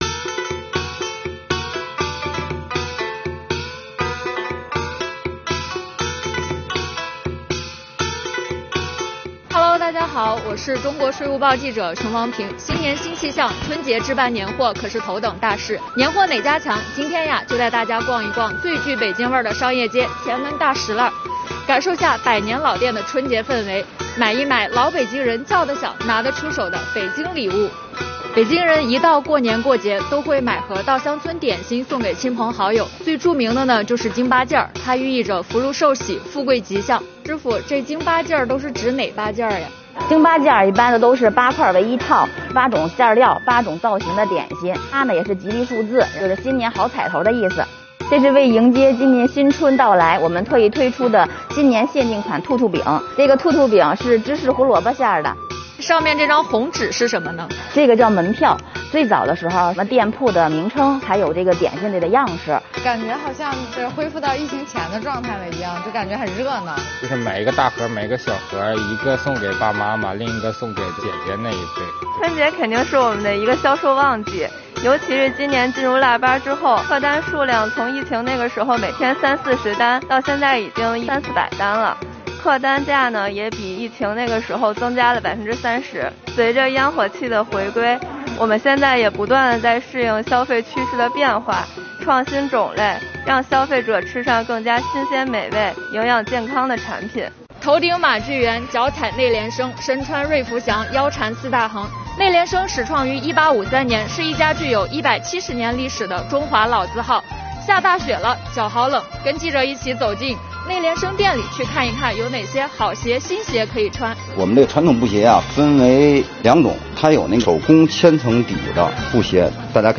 红红的灯笼高高挂起，老北京小吃店门庭若市，百年老字号里人们兴奋地挑选春节礼物……随着疫情防控政策的调整和春节的临近，位于北京前门的著名商业街大栅栏熙熙攘攘，游人如织，处处洋溢着浓浓的年味。
今天，请你跟随本报记者的镜头一起走进大栅栏，感受疫情过后的人间烟火气。